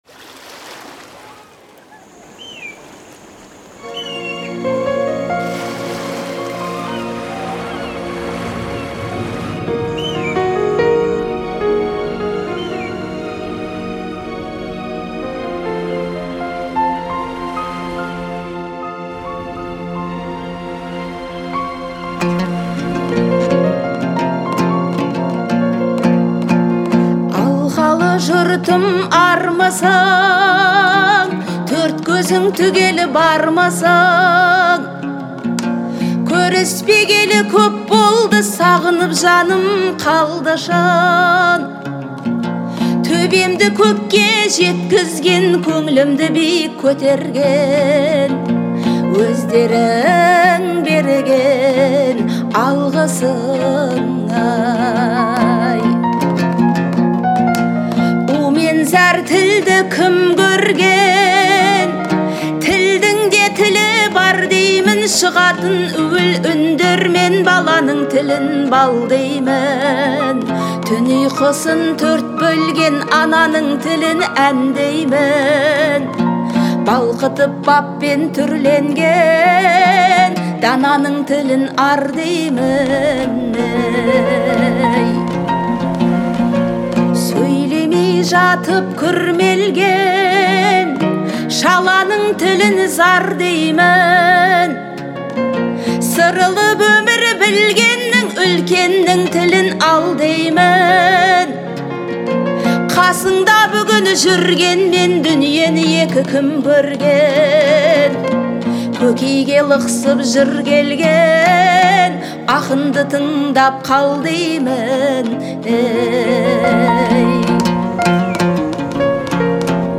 Настроение песни — меланхоличное и ностальгическое.